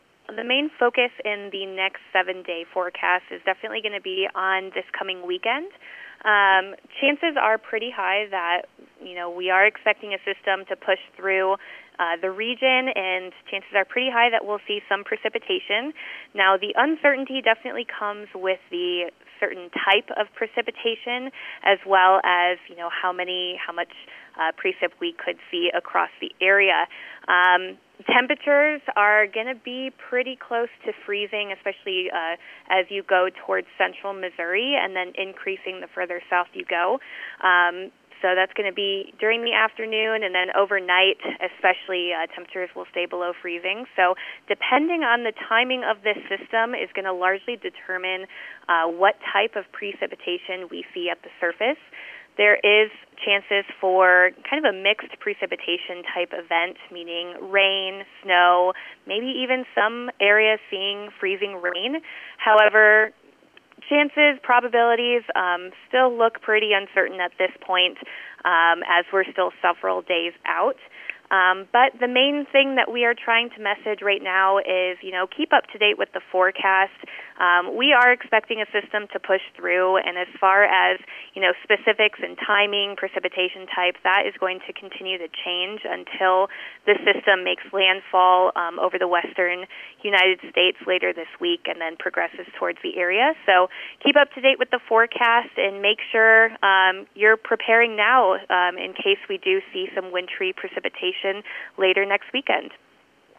Freezing Rain This Week? It’s Possible – A Talk with the National Weather Service